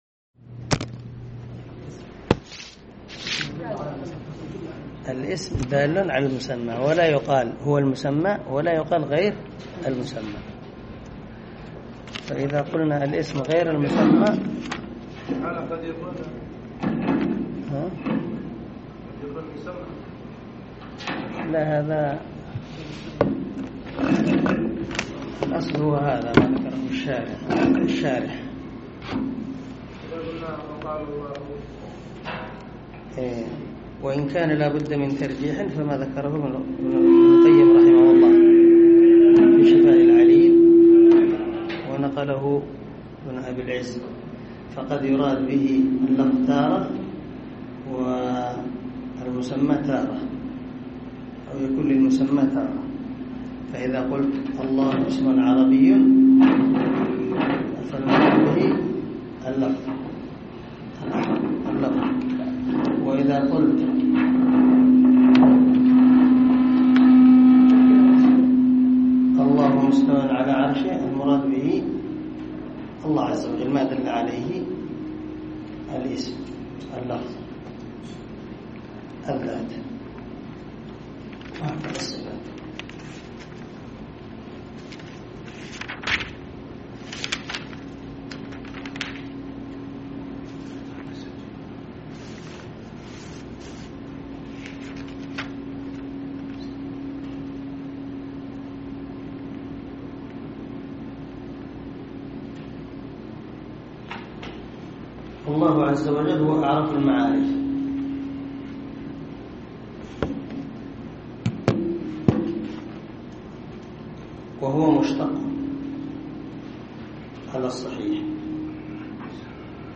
عنوان الدرس: الدرس الثالث
دار الحديث- المَحاوِلة- الصبيحة.